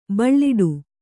♪ baḷḷiḍu